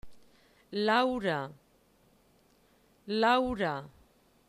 EJERCICIO 1: CORRESPONDENCIA ESCRITURA/PRONUNCIACIÓN